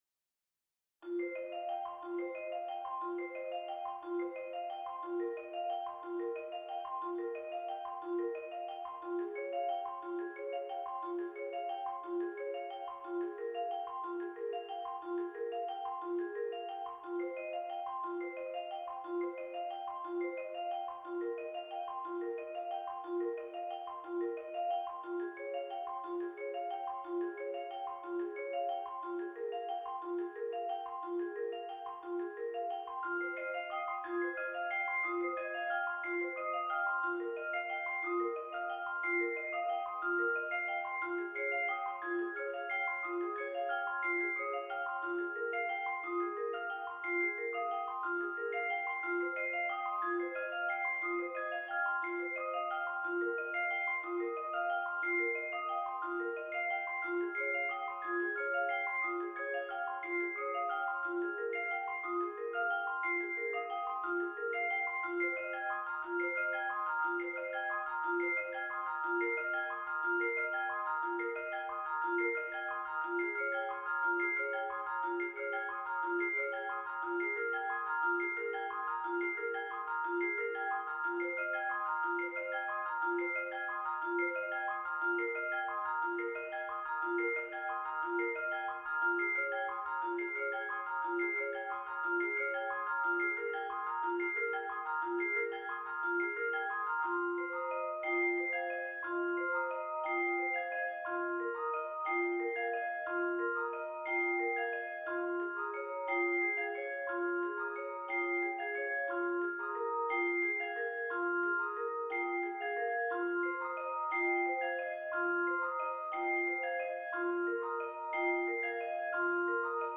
Percussion Ensemble - Vibraphone Duet or Trio